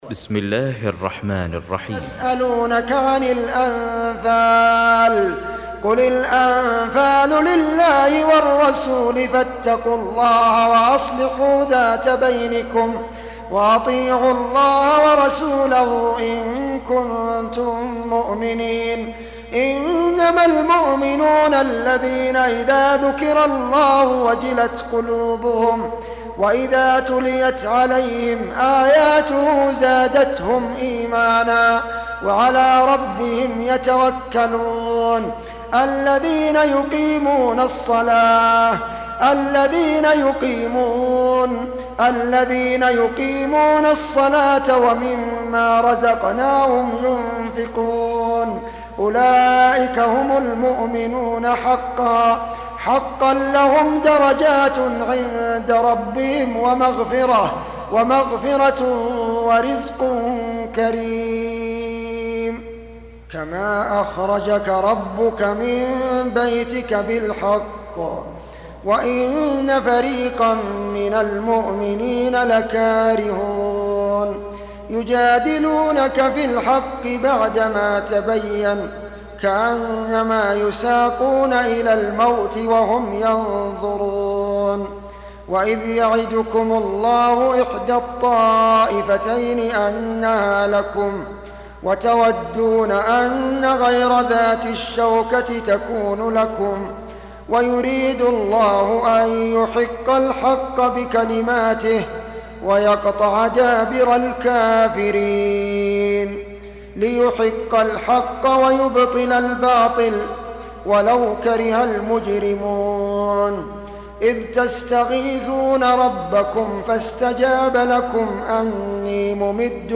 Surah Repeating تكرار السورة Download Surah حمّل السورة Reciting Murattalah Audio for 8. Surah Al-Anf�l سورة الأنفال N.B *Surah Includes Al-Basmalah Reciters Sequents تتابع التلاوات Reciters Repeats تكرار التلاوات